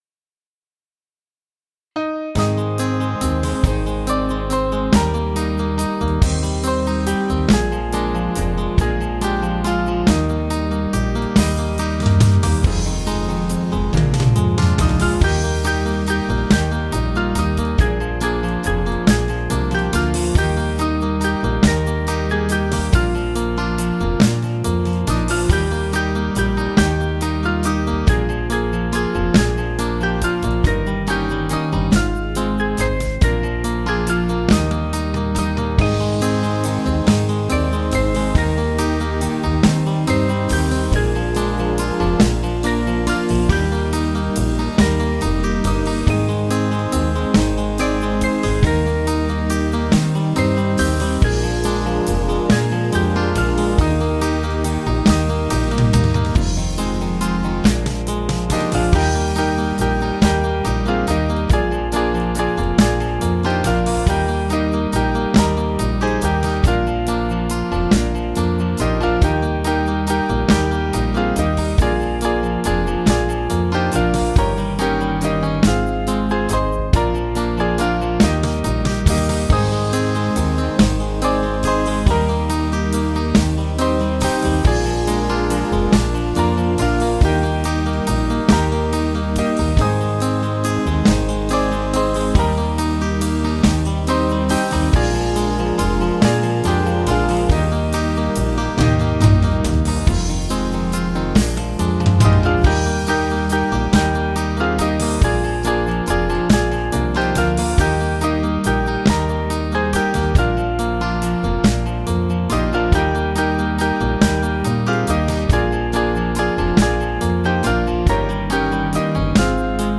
Small Band
(CM)   3/Eb 477.2kb